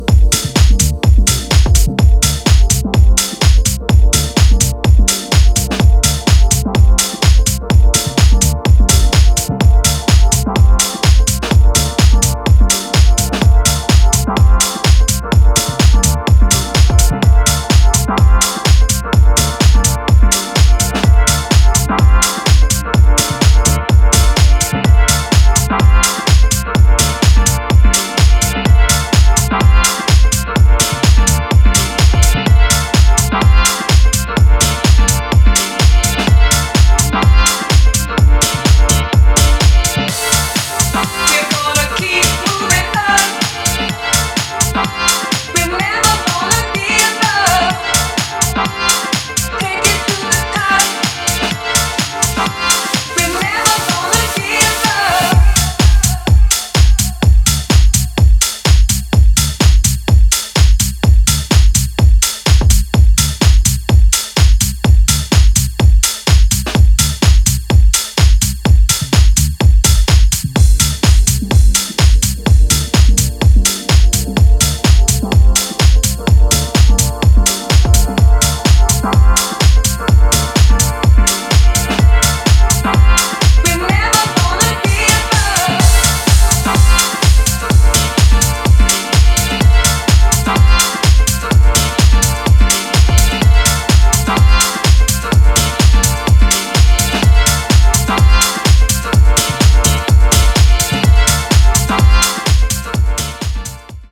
定番的な楽曲からレアブギーまでをDJユースに捌いた